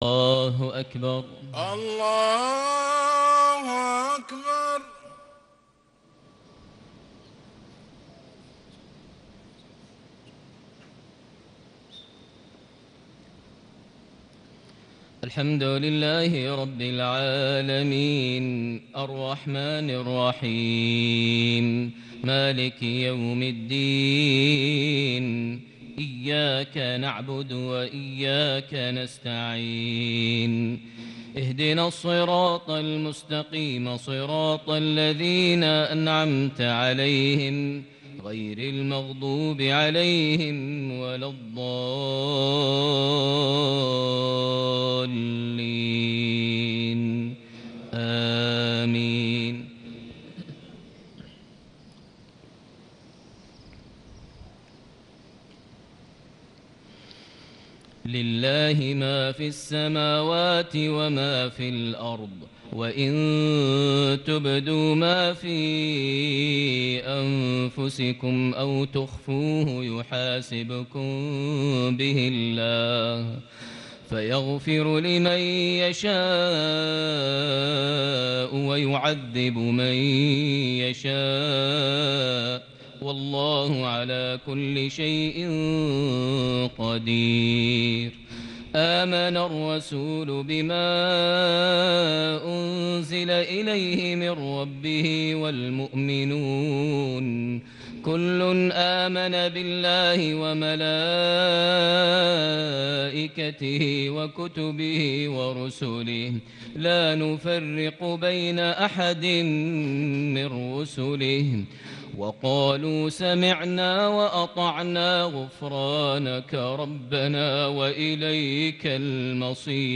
صلاة المغرب ٢٧ ذو القعدة ١٤٣٨هـ خواتيم سورة البقرة > 1438 هـ > الفروض - تلاوات ماهر المعيقلي